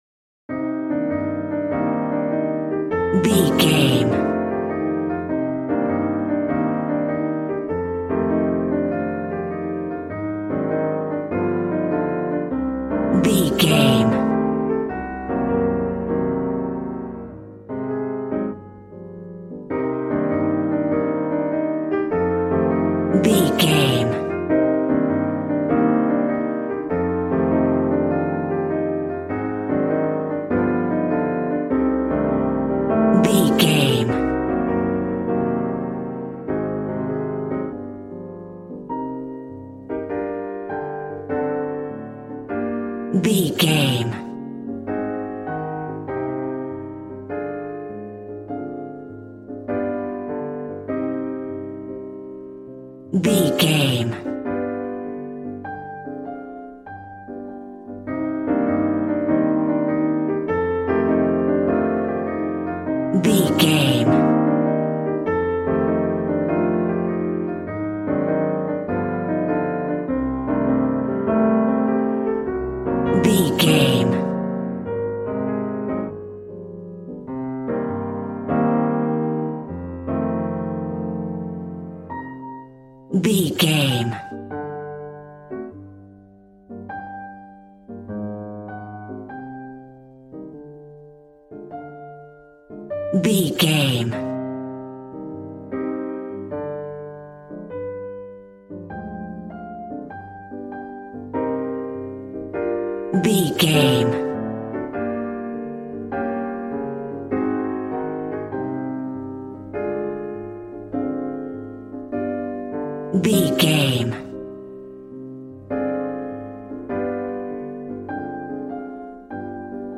Smooth jazz piano mixed with jazz bass and cool jazz drums.,
Aeolian/Minor
piano
drums